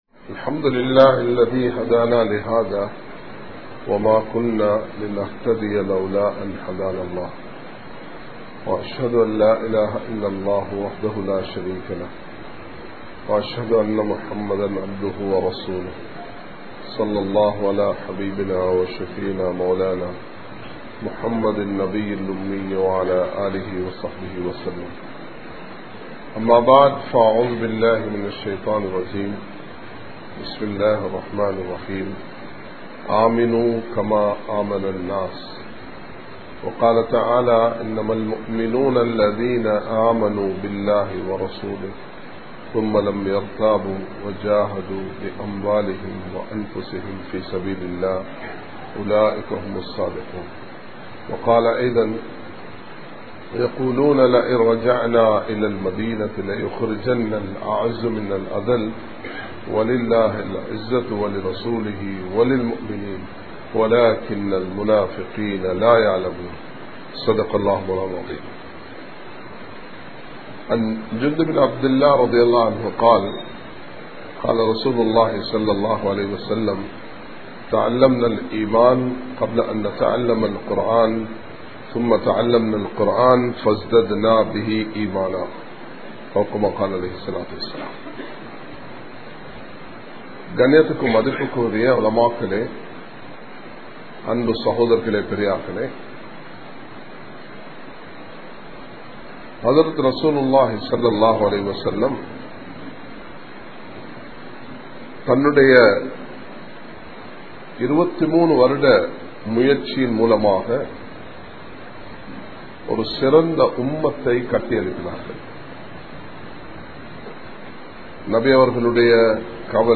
Nabi(SAW)Avarhalin Kavalai (நபி(ஸல்)அவர்களின் கவலை) | Audio Bayans | All Ceylon Muslim Youth Community | Addalaichenai